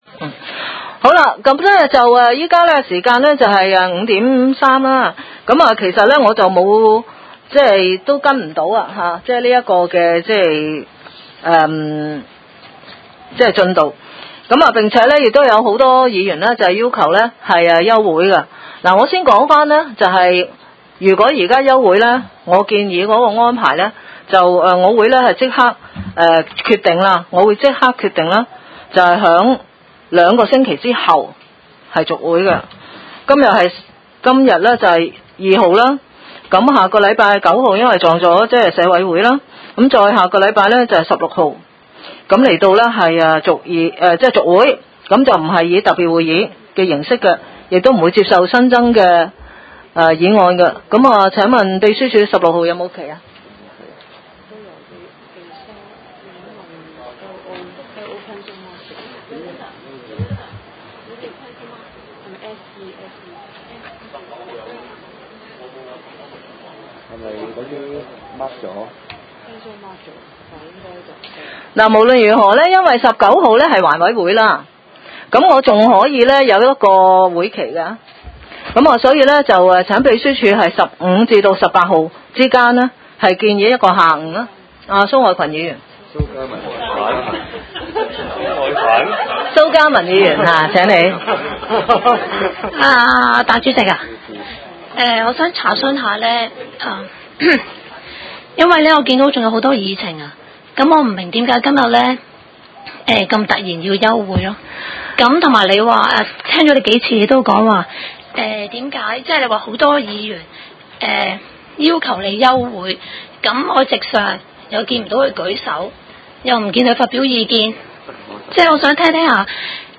屯門區議會會議室